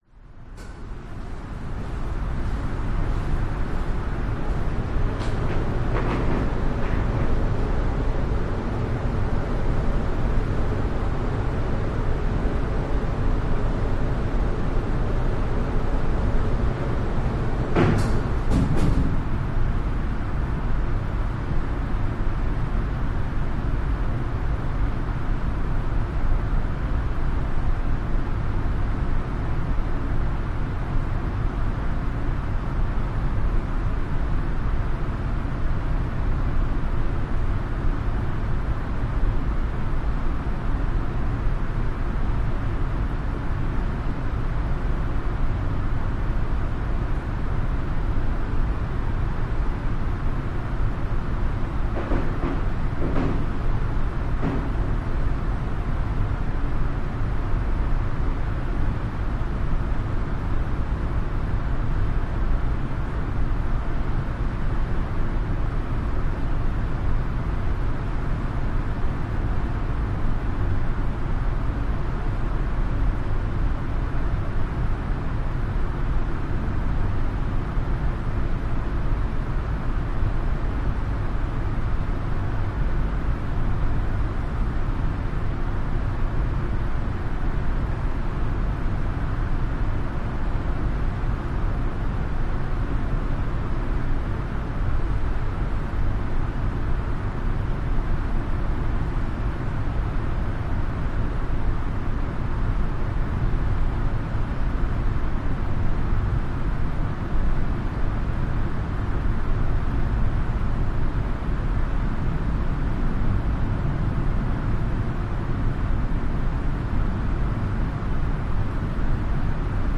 Звуки школы
Звук воздушного шума на школьной лестничной площадке